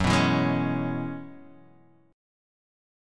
F#9sus4 chord